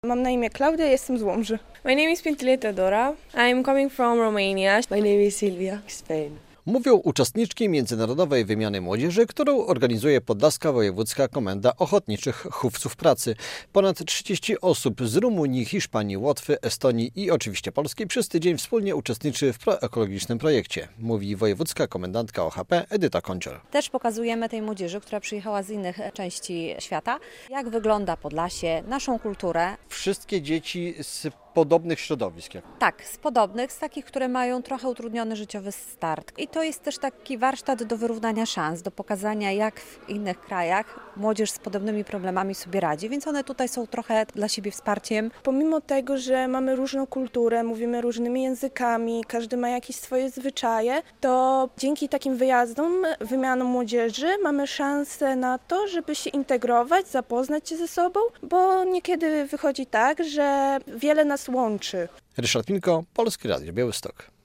Młodzież z pięciu krajów na warsztatach zorganizowanych przez Podlaską Wojewódzką Komendę Ochotniczych Hufców Pracy - relacja